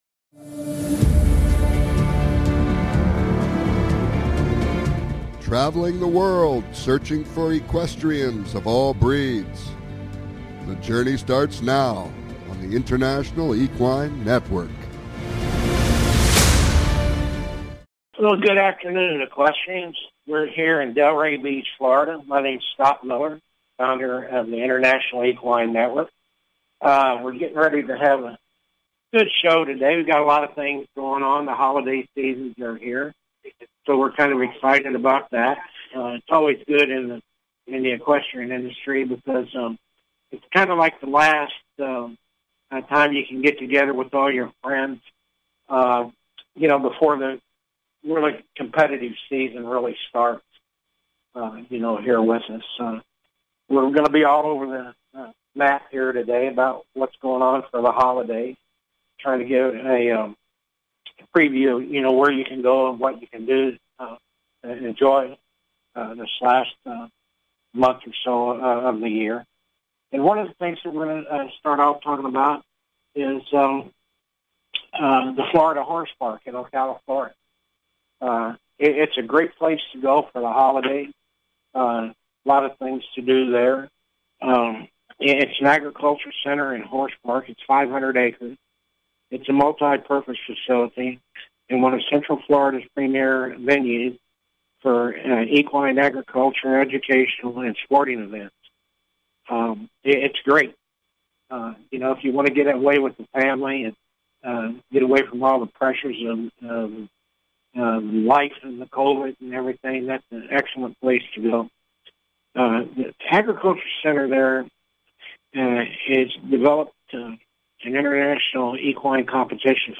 Talk Show Episode
Calls-ins are encouraged!